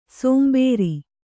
♪ sōmbēri